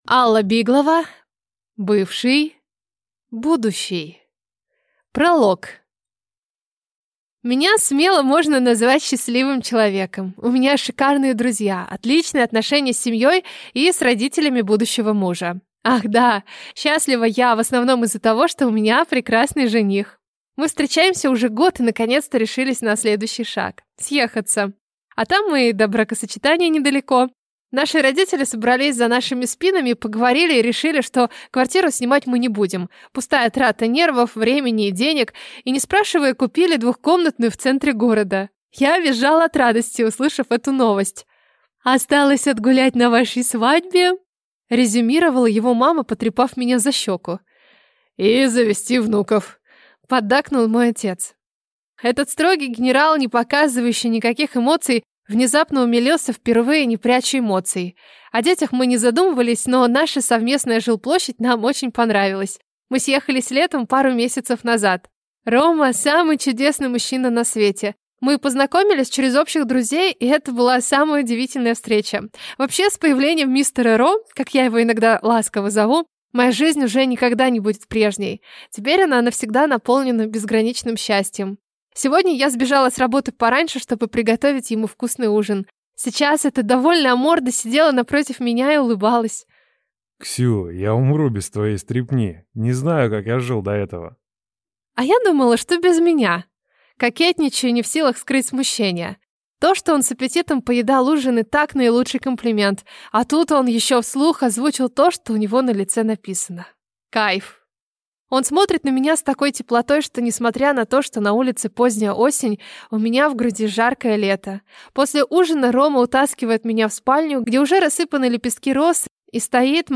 Аудиокнига Бывший… Будущий?!